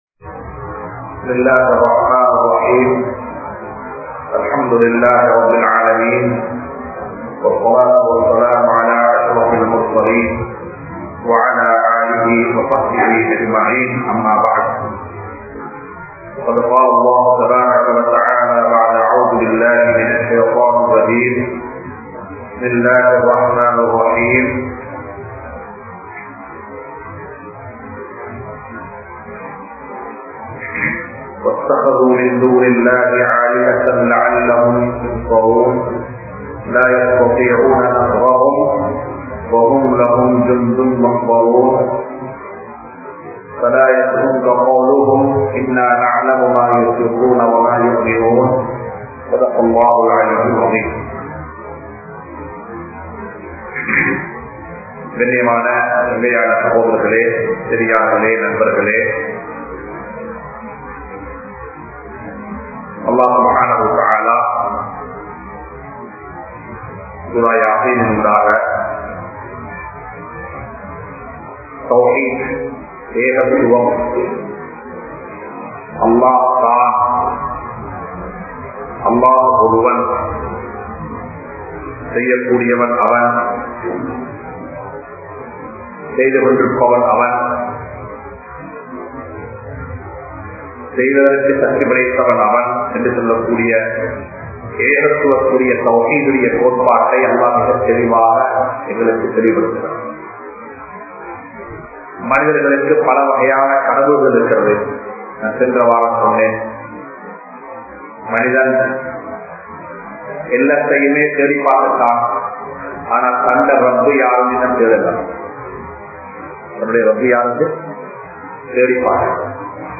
Surah Yaseen(Thasfseer) | Audio Bayans | All Ceylon Muslim Youth Community | Addalaichenai